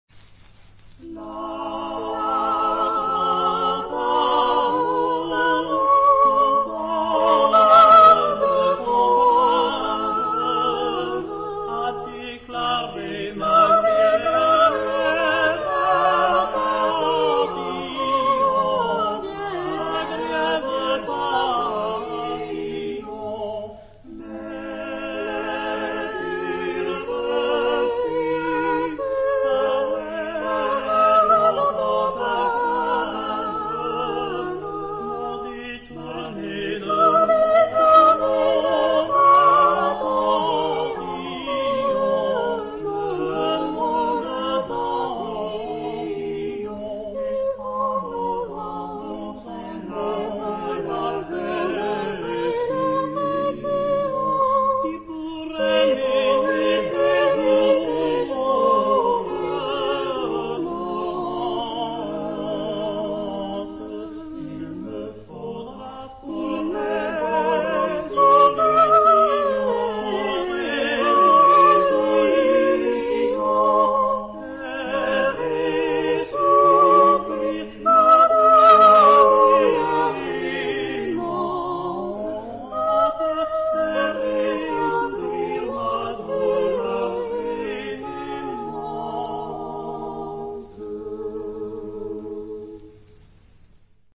Genre-Stil-Form: Renaissance ; weltlich ; Liedsatz
Chorgattung: SATB  (4 gemischter Chor Stimmen )
Tonart(en): C-Dur